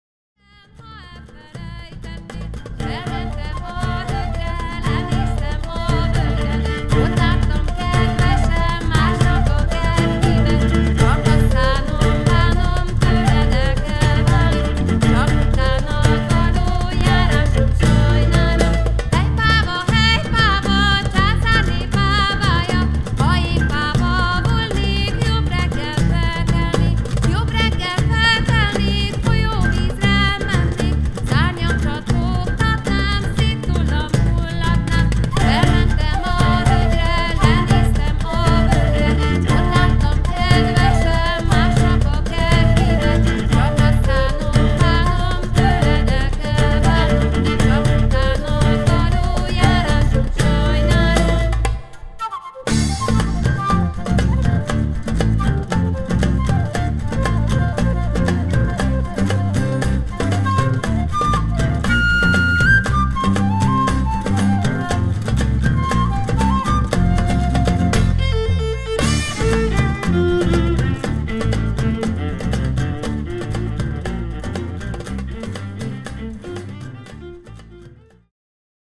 Traditional Moldvian folk song